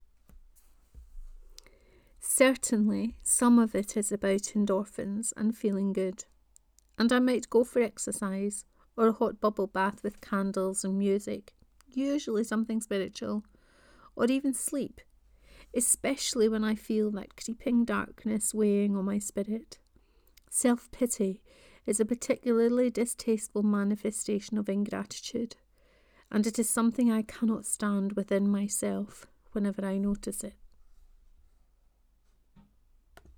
What brings you to life 4: Reading of this post.